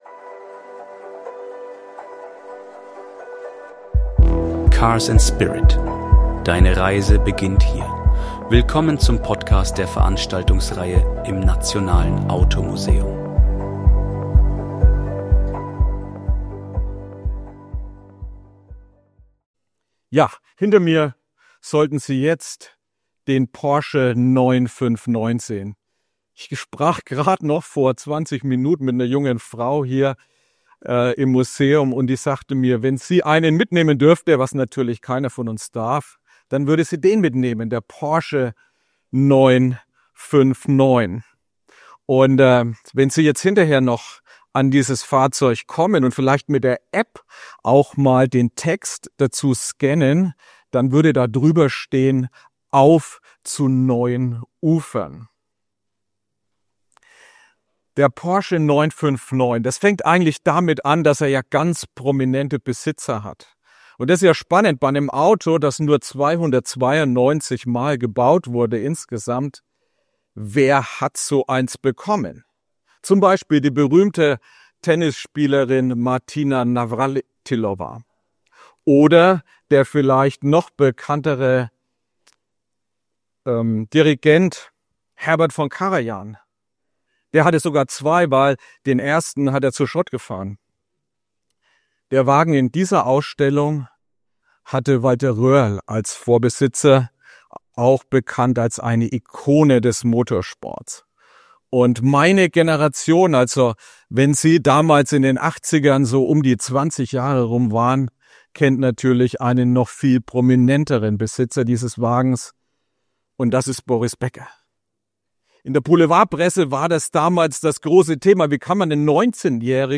Die Veranstaltungsreihe im Nationalen Automuseum.
spiritueller Inspiration – direkt aus dem Nationalen Automuseum!